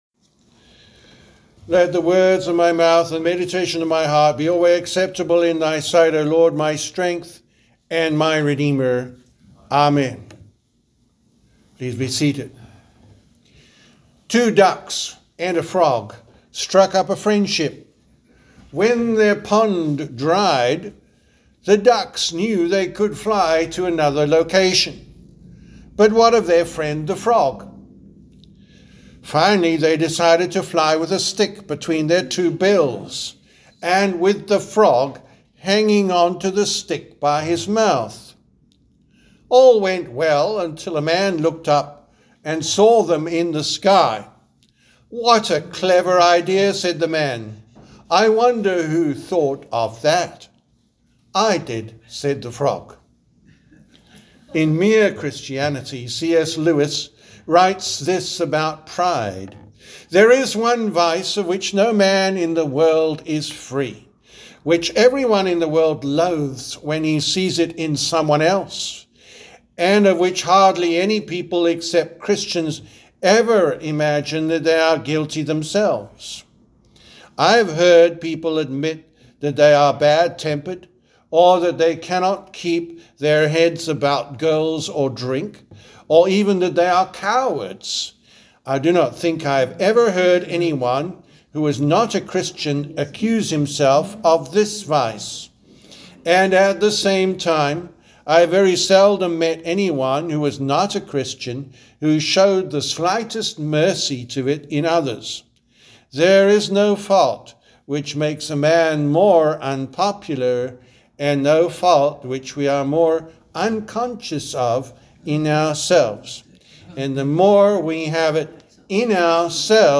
The Sermon for Sunday, August 31st, the Eleventh Sunday after Trinity
Categories: Sermons